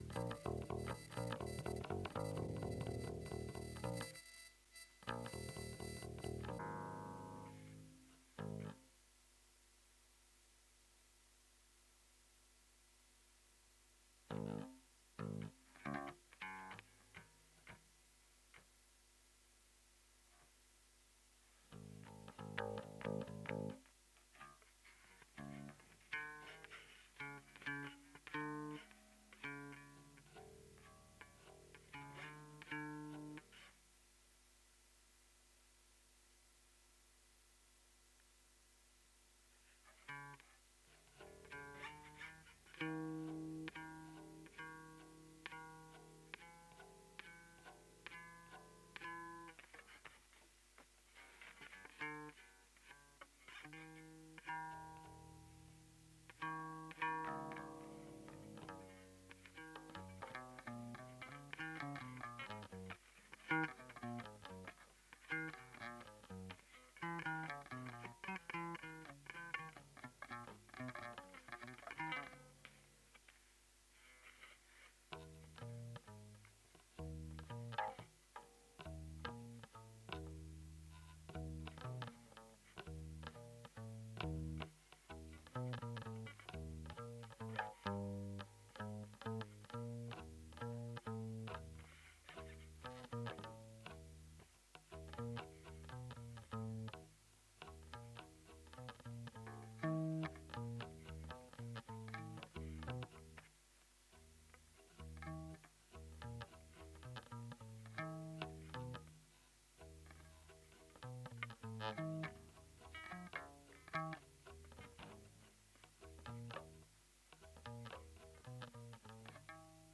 Jetzt habe ich aber mal den XLR-Ausgang meines Bass-Amps mit dem nächsten freien Input des x32 verbunden, Was beim Bass-Anschluss funktioniert hat: In Reaper werden Ausschläge angezeigt Reaper kann den Testtrack rendern zu einer hörbaren MP3, hab ich angehängt (bass ab etwa 30 sek). Sound ist zwar sch°°°°, aber hauptsache, es kommt mal was Wo allerdings nix geht, ist beim Kophörer.